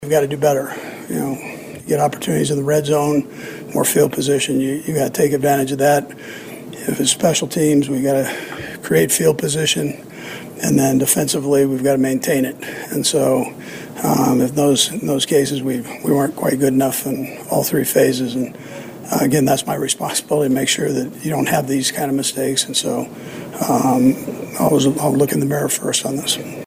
The Chiefs’ ten-year playoff streak ended with the loss to Los Angeles. After the game, Head Coach Andy Reid talked about the loss